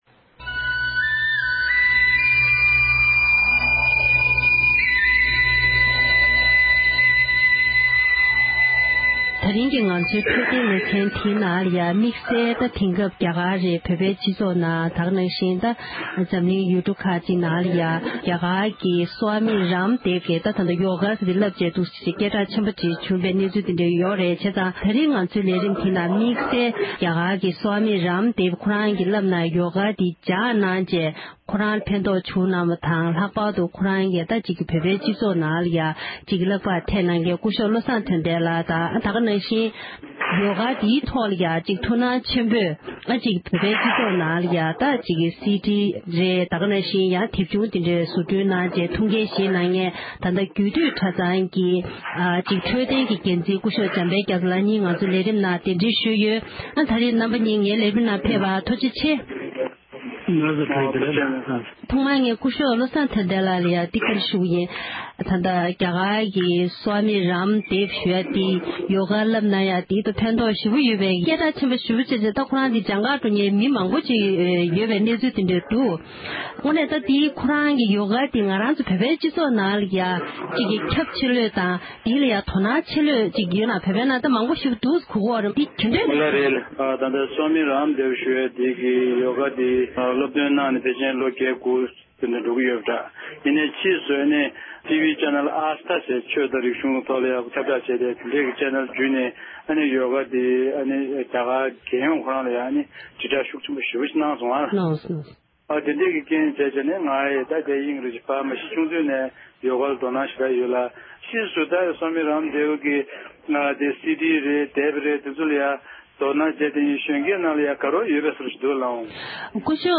འབྲེལ་ཡོད་མི་སྣ་གཉིས་ལ་བཀའ་འདྲི་ཞུས་པར